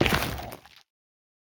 Minecraft Version Minecraft Version snapshot Latest Release | Latest Snapshot snapshot / assets / minecraft / sounds / block / netherrack / step2.ogg Compare With Compare With Latest Release | Latest Snapshot
step2.ogg